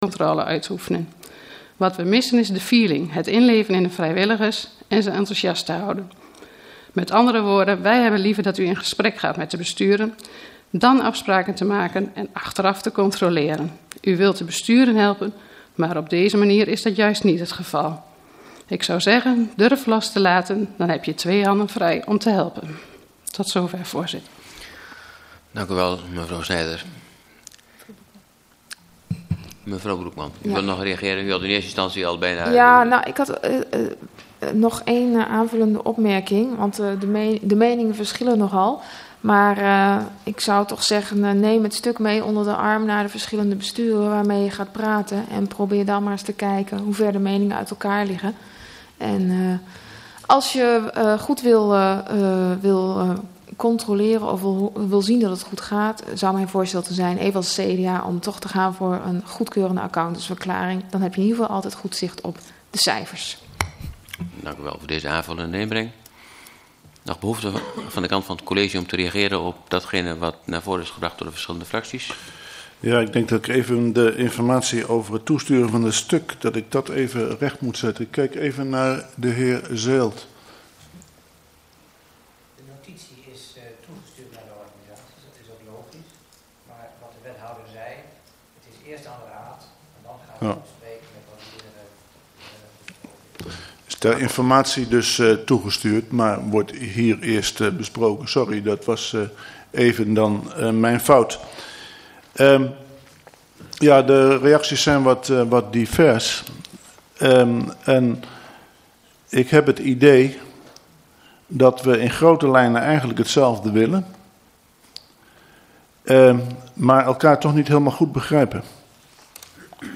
Raadscommissie 04 juni 2012 19:30:00, Gemeente Dalfsen